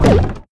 barrel_roll_02.wav